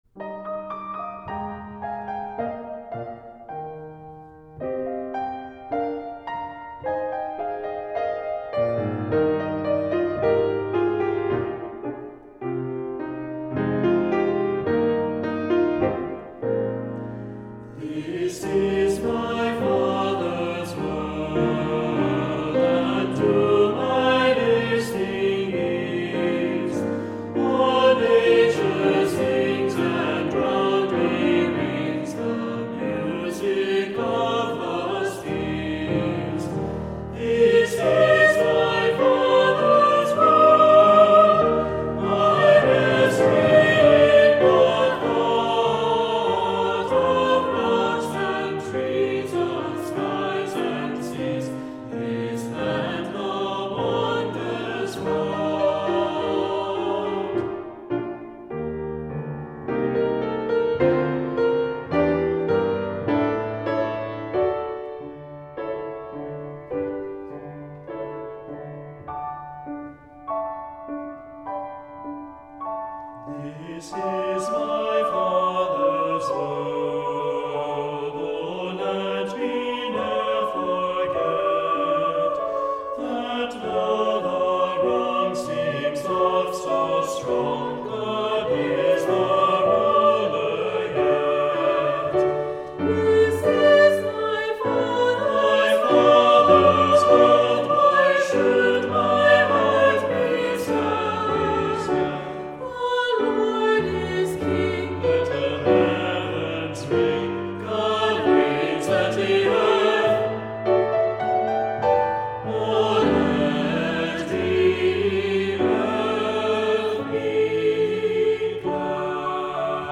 Voicing: Two-part equal; Two-part mixed